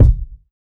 KICK BOOM.wav